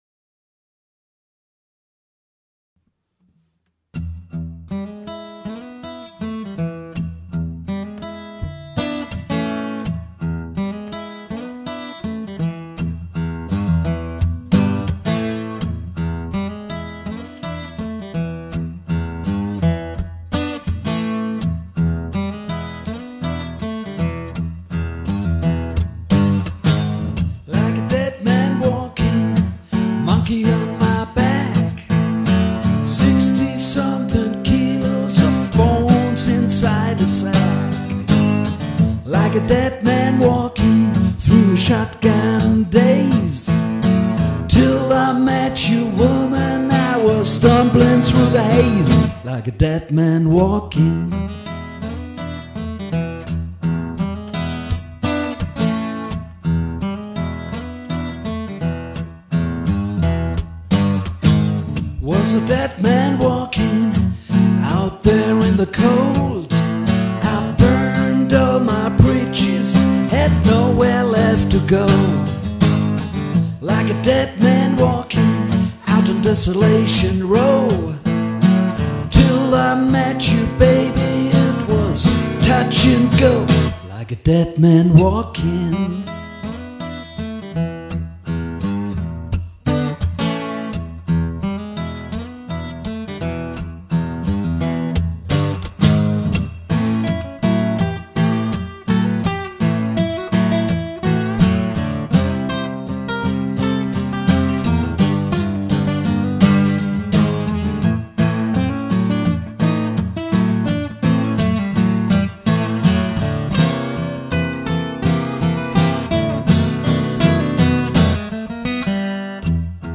Ich bin dann soweit auch durch, es ist teilweise sehr unsauber geworden, aber was solls. Ich habe 2 Takes aufgenommen, die beide ihre Höhen und Tiefen hatten und habe den 2. dann einfach genommen. your_browser_is_not_able_to_play_this_audio Wenn ich das richtig sehe